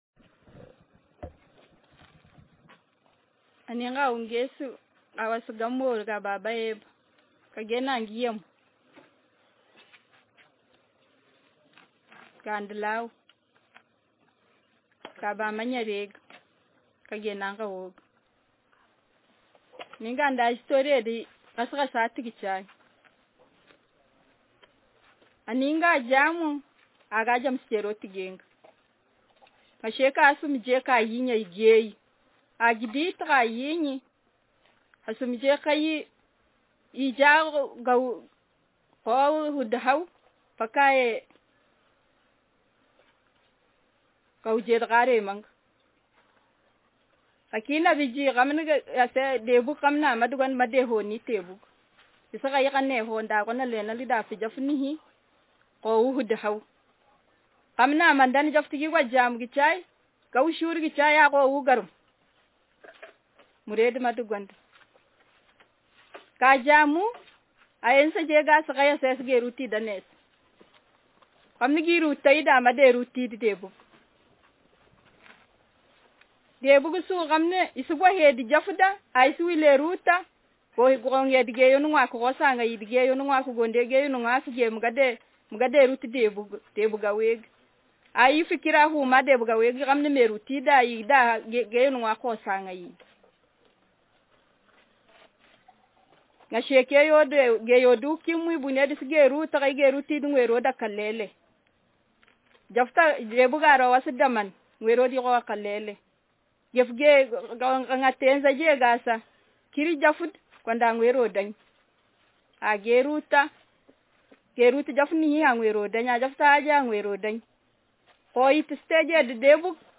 Speaker sexm
Text genrepersonal narrative